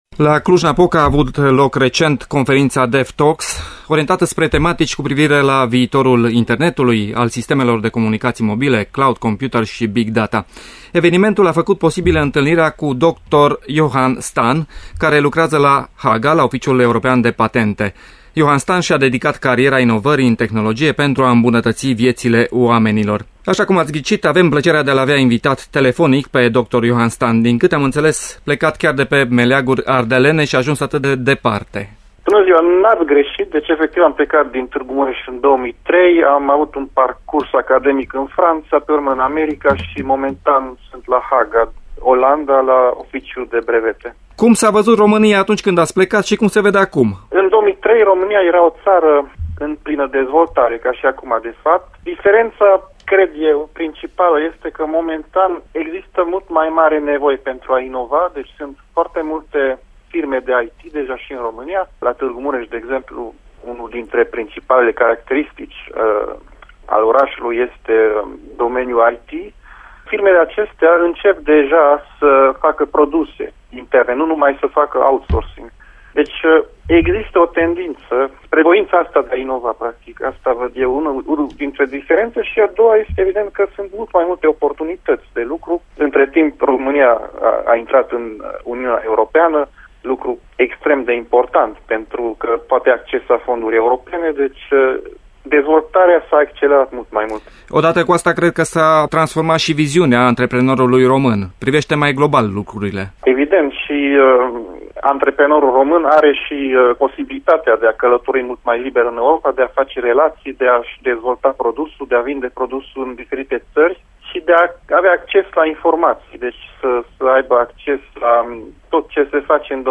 Este a 3-a parte a unui interviu mai amplu, pe care puteți să-l ascultați integral aici: https